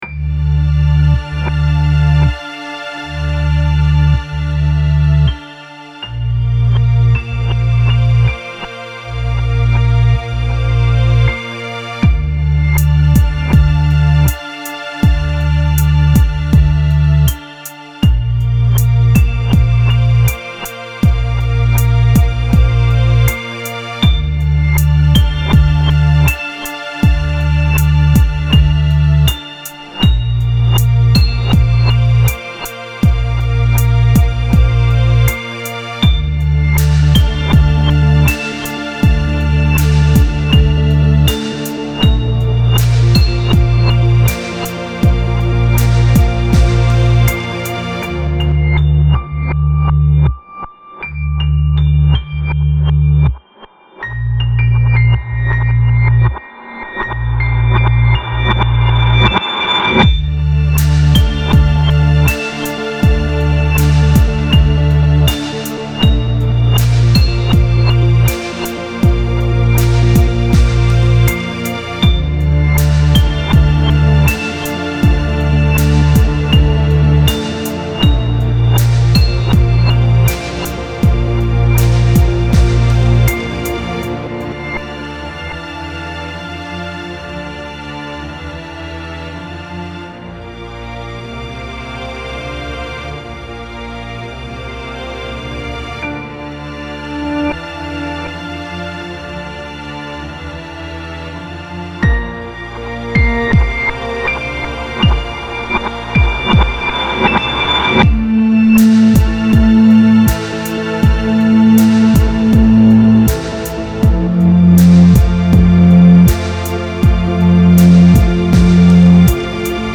Style Style EDM/Electronic
Mood Mood Calming
Featured Featured Bass, Drums, Piano +2 more
BPM BPM 80